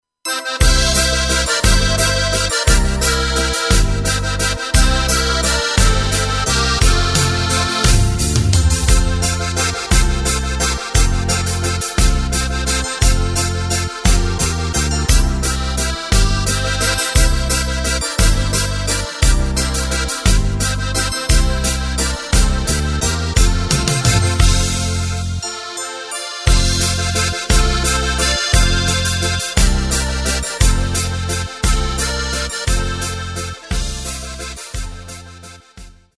Takt:          3/4
Tempo:         174.00
Tonart:            F
Walzer aus dem Jahr 2009!
Playback mp3 Demo